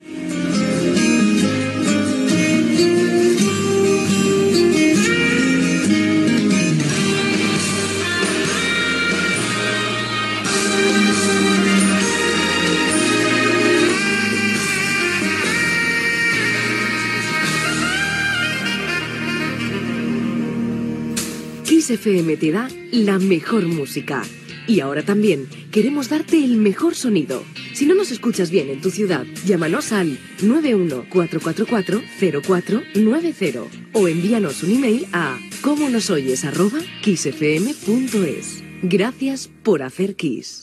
Indicatiu instrumental i demanda de controls de recepció